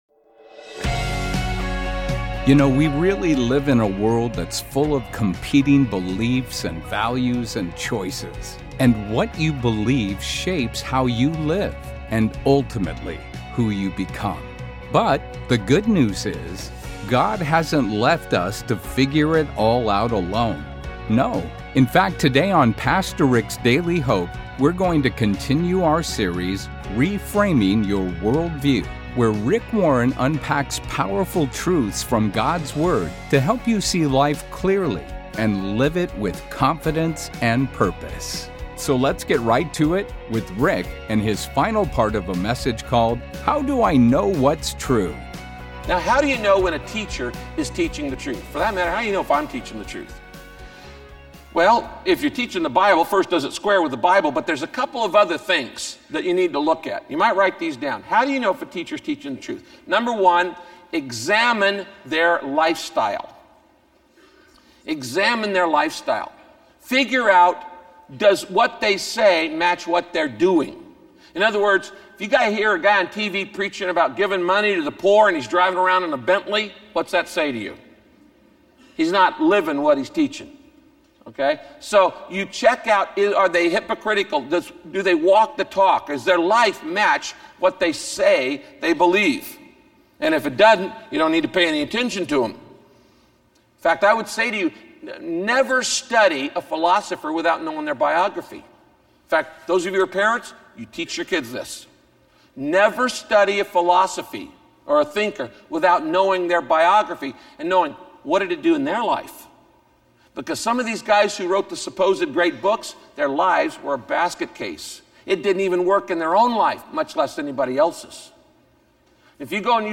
Pastor Rick gives "next steps" for moving forward in faith in this audio teaching.